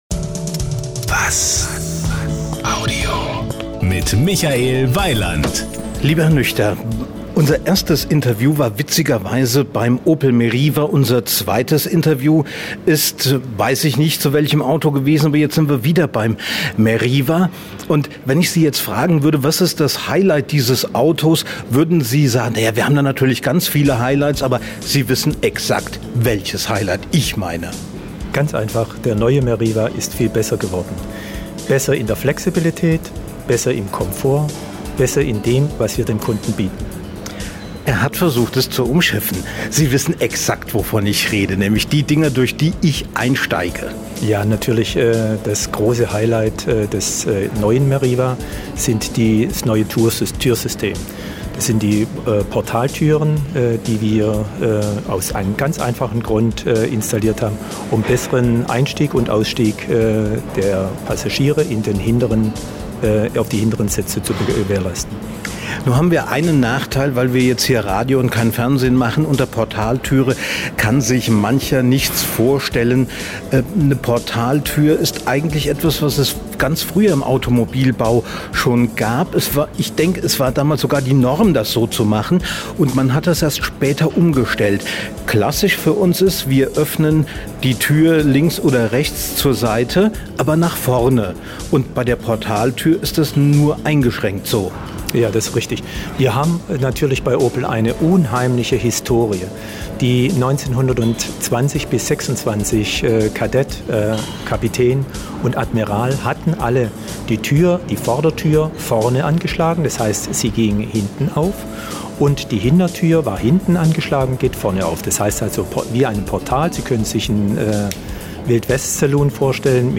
Sie sind hier: Start » Interviews » Interviews 2009